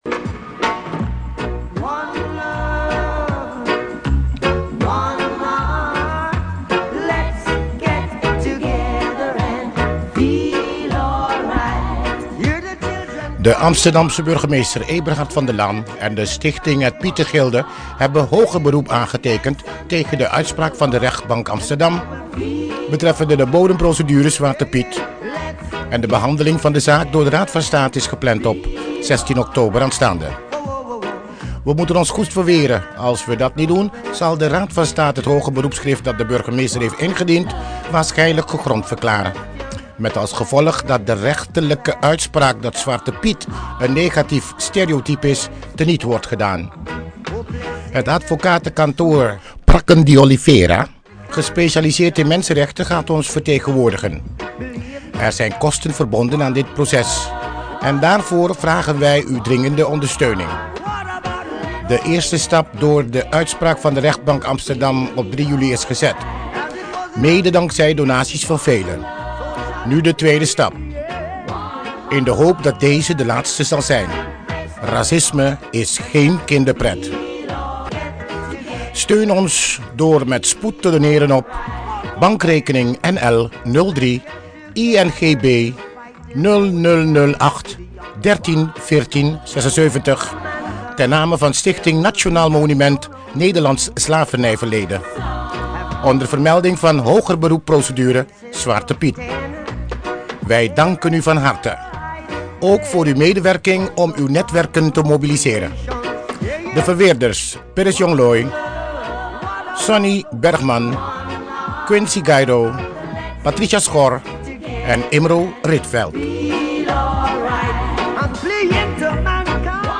(Een mondelinge oproep)